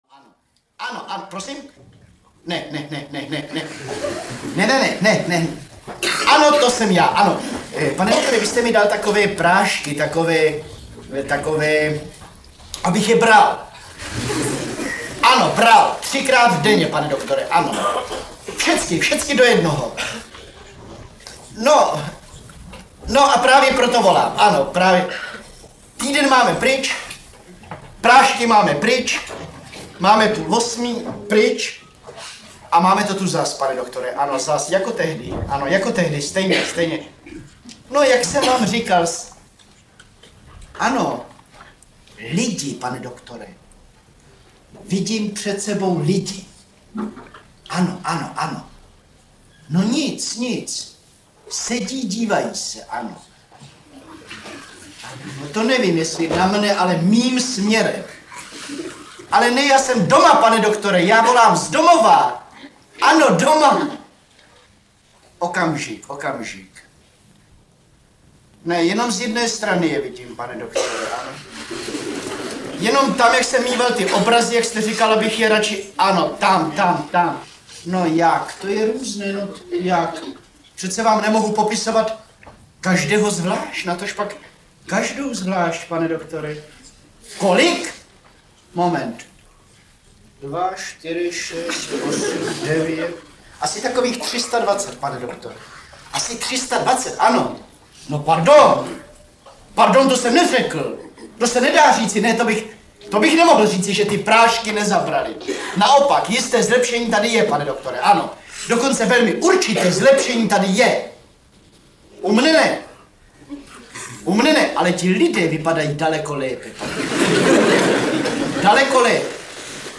Živý záznam tohoto vystoupení